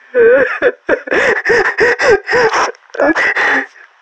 NPC_Creatures_Vocalisations_Robothead [96].wav